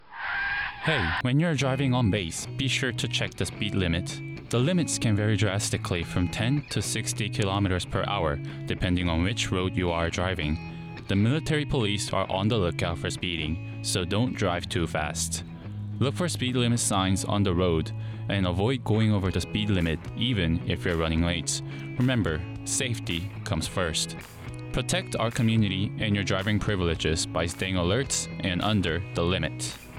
A 30-second spot about driving within the speed limits and doing so to protect the drivers' driving privileges and protect the pedestrians.